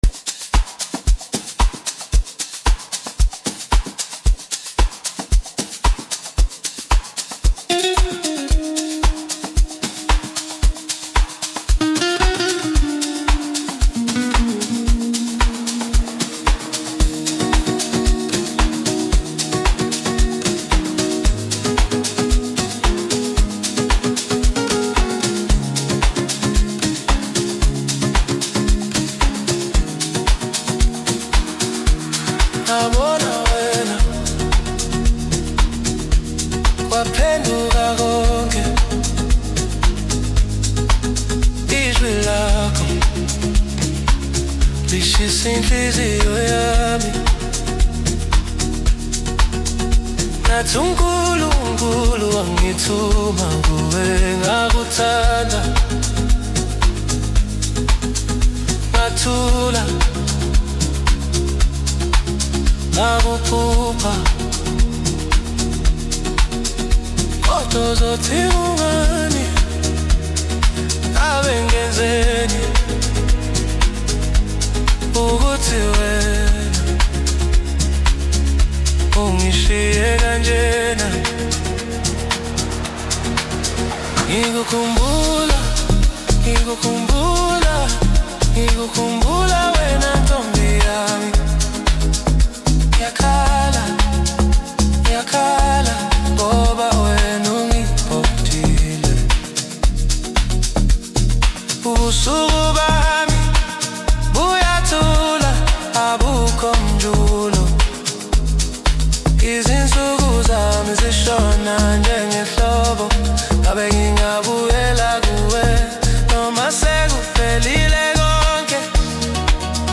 Home » Amapiano
South African singer-songsmith